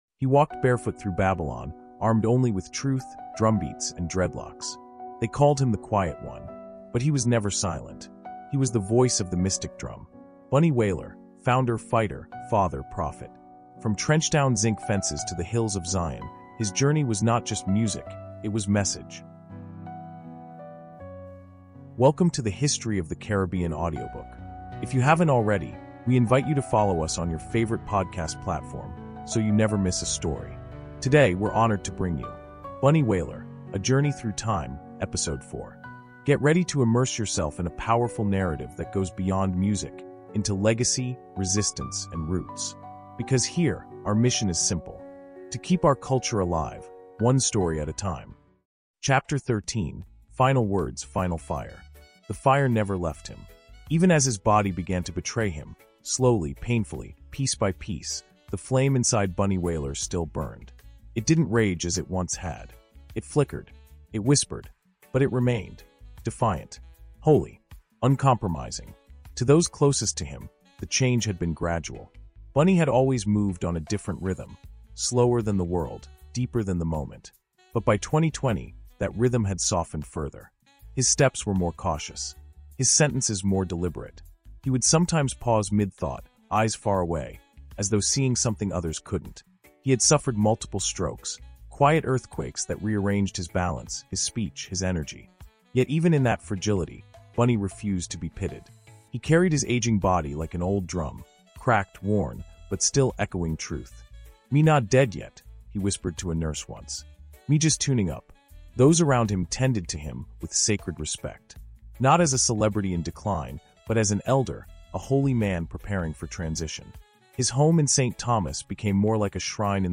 Bunny Wailer: Voice of the Mystic Drum is a powerful 16-chapter audiobook chronicling the life of reggae legend Neville “Bunny” Livingston—co-founder of The Wailers, spiritual guardian of roots reggae, and the last lion of a cultural revolution. From Nine Mile to Trenchtown, from spiritual exile to global prophecy, this immersive storytelling journey reveals the heart, fire, and message of a man who never bent to Babylon.